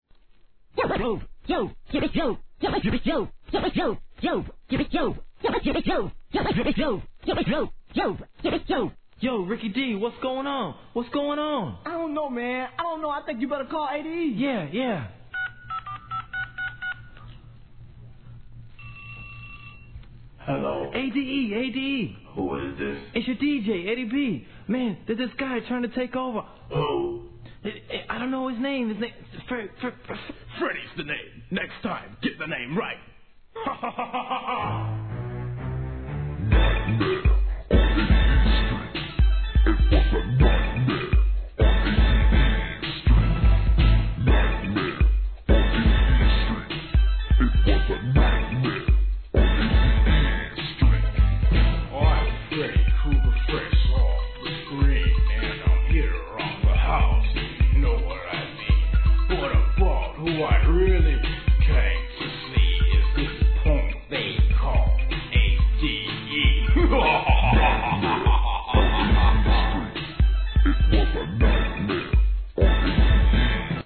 1988年のインディー物エレクトロOLD SCHOOL "HIP HOP"収録!!